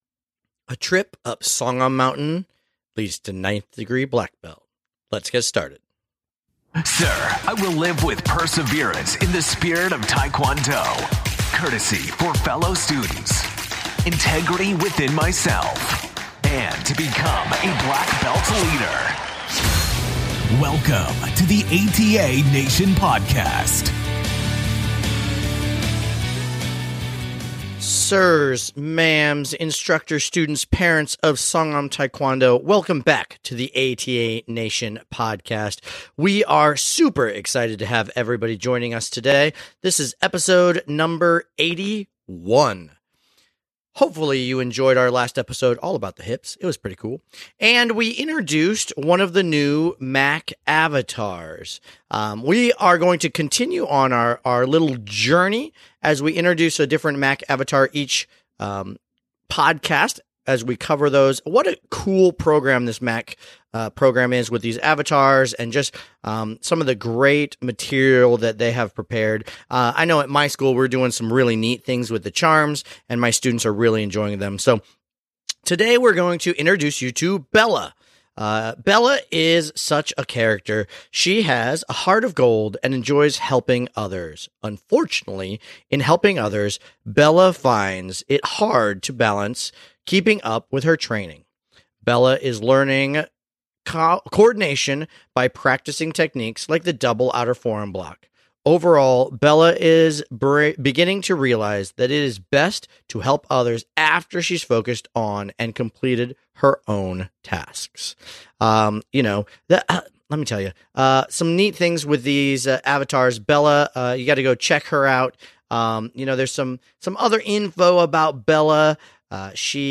This week we feature an interview